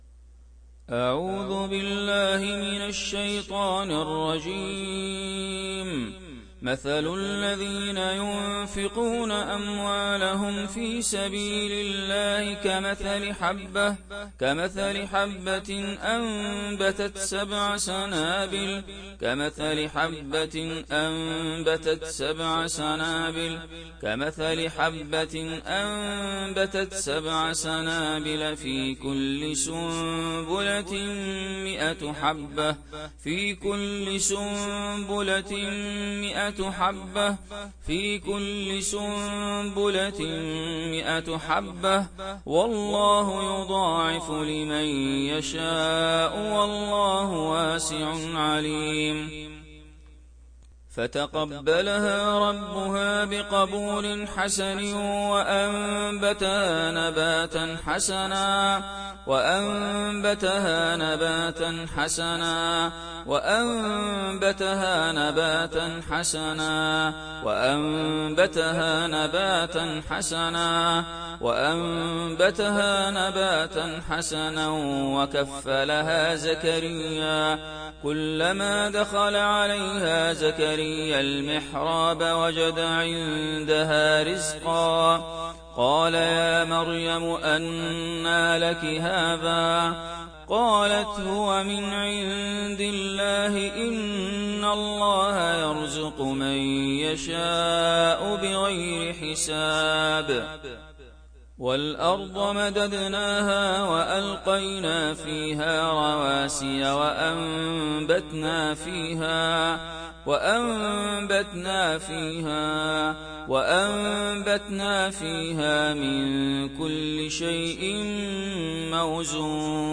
চুল পড়া রোধের রুকইয়াহ — Ruqyah for hair fall